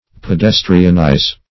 Search Result for " pedestrianize" : The Collaborative International Dictionary of English v.0.48: Pedestrianize \Pe*des"tri*an*ize\, v. i. [imp.
pedestrianize.mp3